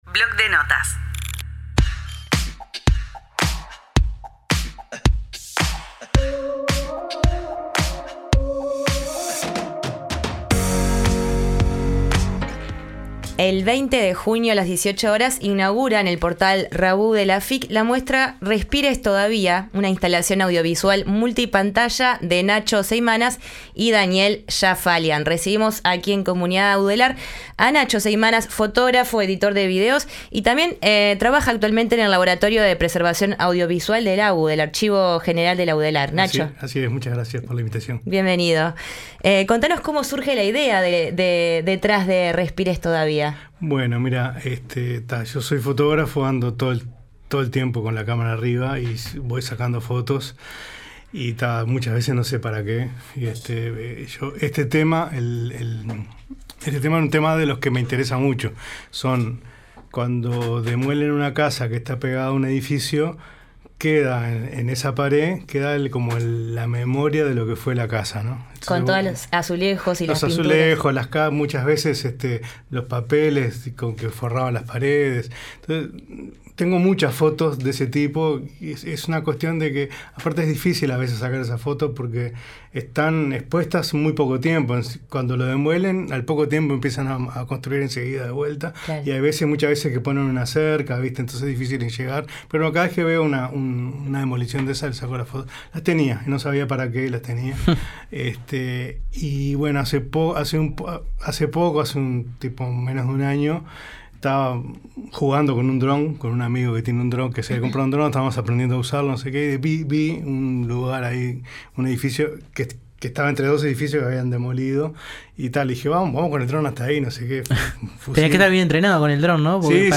En la entrevista central recibimos a Edgardo Ortuño, director de Ose por el Frente Amplio con quien conversamos sobre la construcción de una nueva planta potabilizadora propuesta en el Proyecto Neptuno.